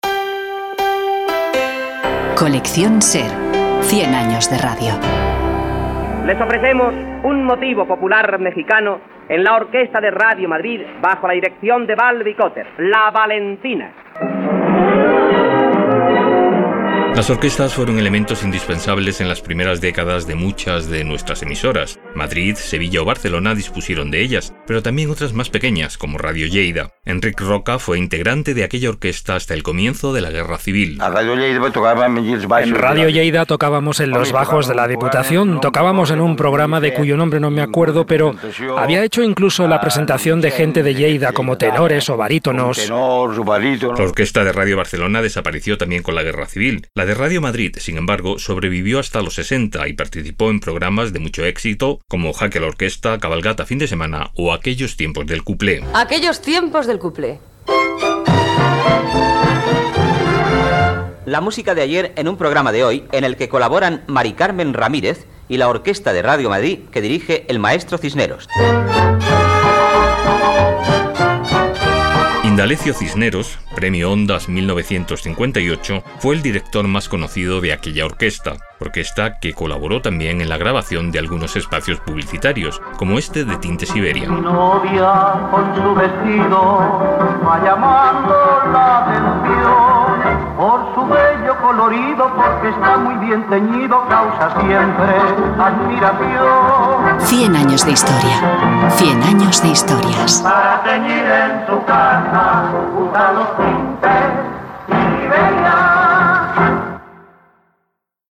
Careta del programa.
Entreteniment